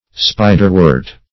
Spiderwort \Spi"der*wort`\, n. (Bot.)